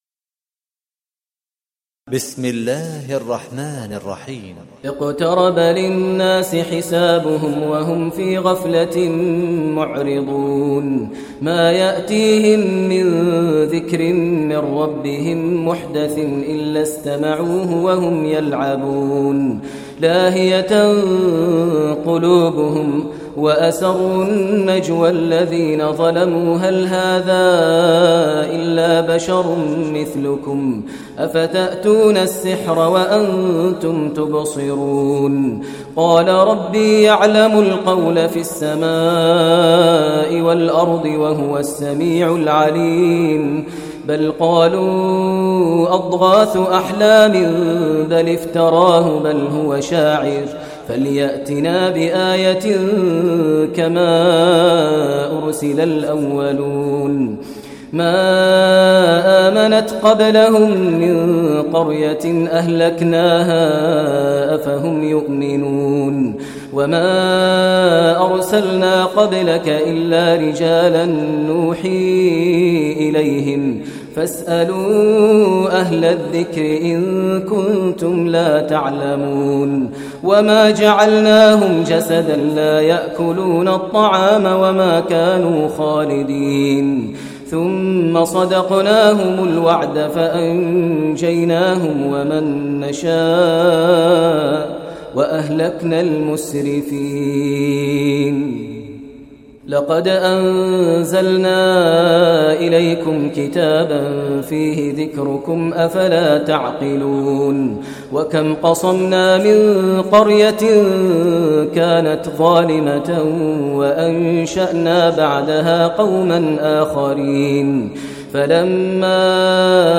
Surah Anbiya Recitation by Maher al Mueaqly
Surah Anbiya is 21 chapter of Holy Quran. Listen online mp3 tilawat / recitation in Arabic recited by Sheikh Maher al Mueaqly.